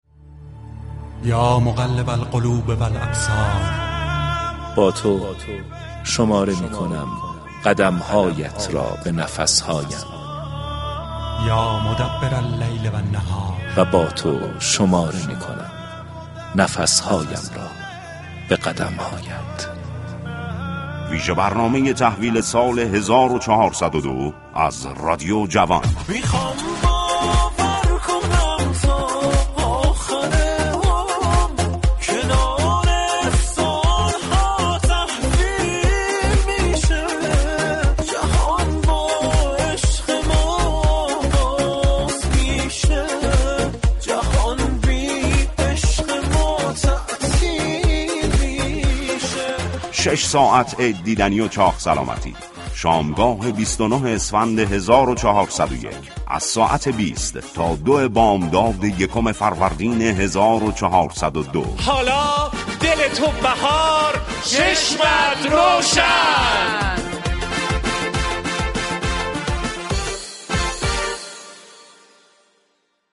این برنامه با محوریت طنز و سرگرمی خواهد بود كه با موسیقی های شاد و پرانرژی به استقبال بهار خواهد رفت.